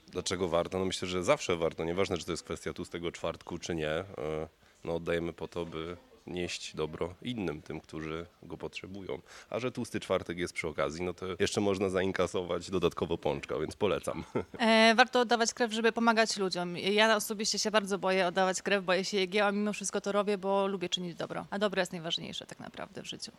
SONDA.mp3